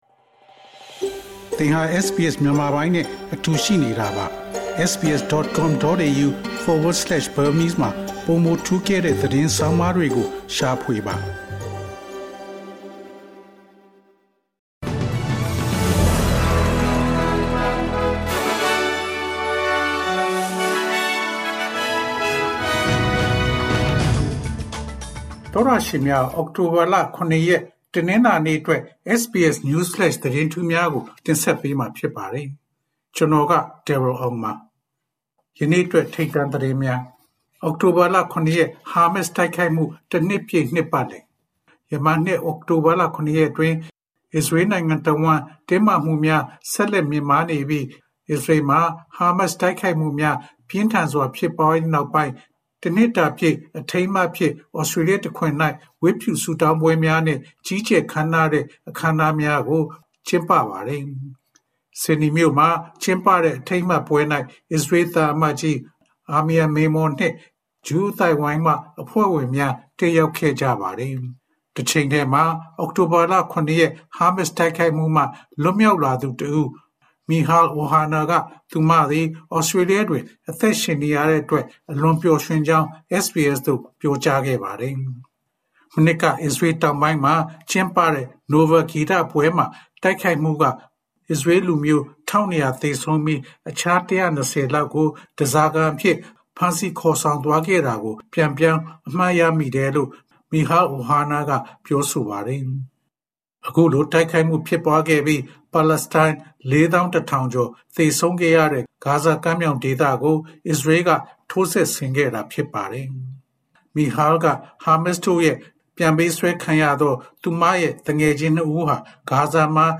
အောက်တိုဘာလ ၇ ရက် တနင်္လာနေ့ SBS Burmese News Flash သတင်းများ။